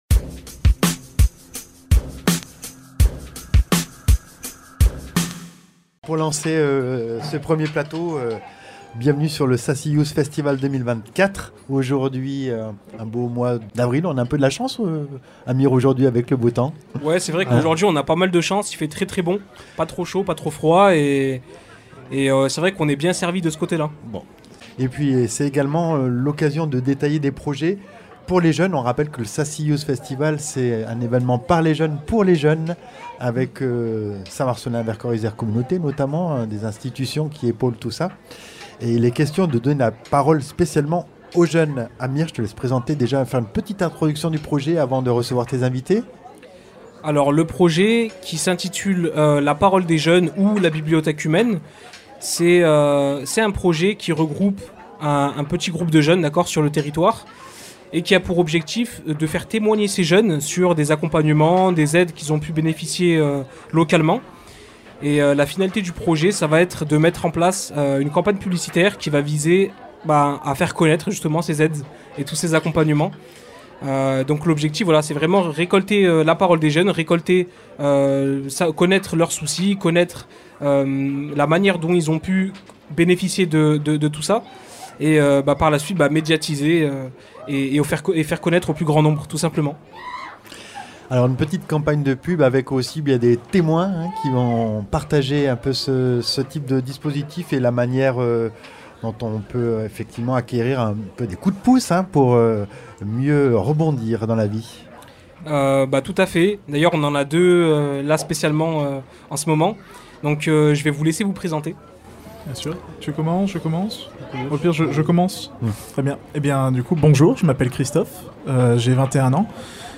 Plateaux radiophoniques depuis le parvis du Diapason à St Marcellin dans le cadre du Sassy youth festival 2024.